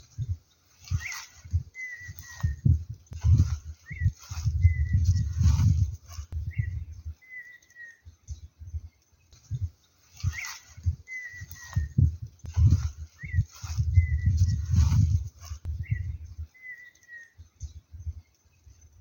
Colorada (Rhynchotus rufescens)
Nombre en inglés: Red-winged Tinamou
Localidad o área protegida: Eco Pousada Rio dos Touros, Urupema, Sc, Brasil
Condición: Silvestre
Certeza: Observada, Vocalización Grabada